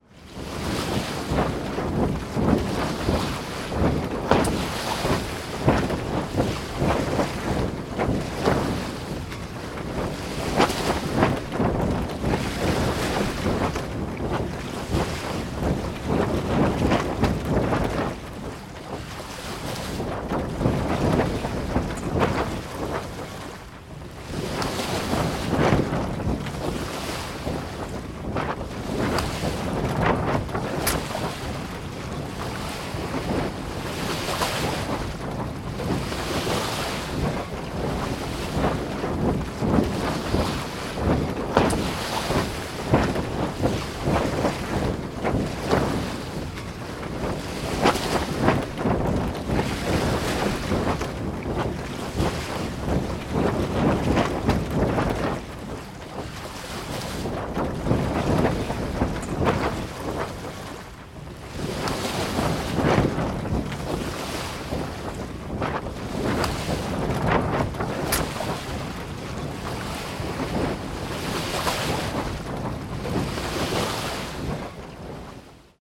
Погрузитесь в атмосферу морских путешествий с коллекцией звуков паруса: шелест натянутой ткани, ритмичный стук волн о борт, крики чаек.
Шепот паруса в морском ветру